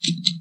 描述：Los sonidos grabados de todos los objetos que no son papel